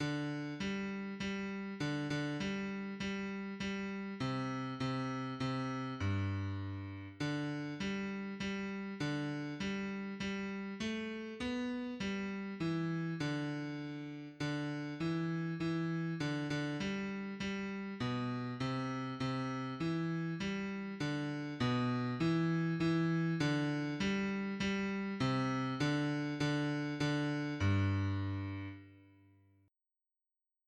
Para aprender la melodía os dejo estos MIDIS, con la voz principal destacada por encima del resto.
en-la-mas-fria-noche-midi-b.mp3